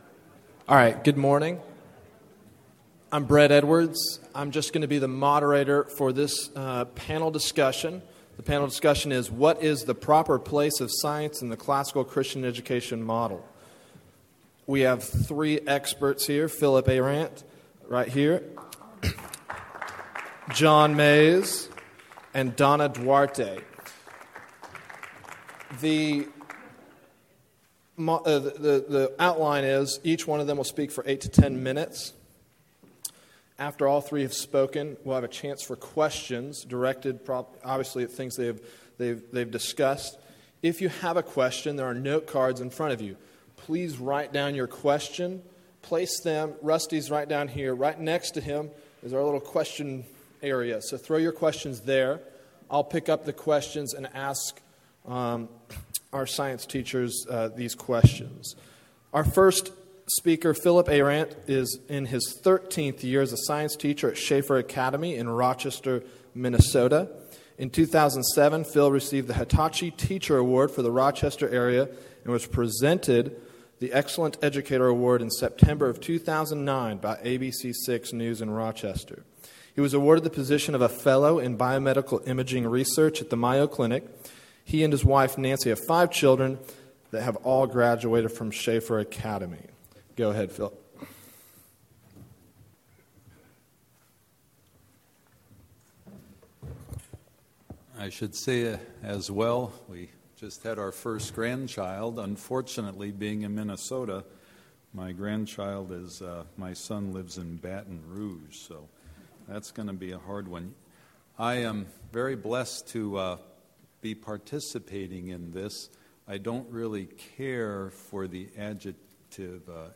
What is the Proper Place of Science in the Classical Christian Education Model? – Panel | ACCS Member Resource Center
2012 Workshop Talk | 1:06:35 | All Grade Levels, Science
What is the Proper Place of Science in the Classical Christian Educational Model Panel Discussion.mp3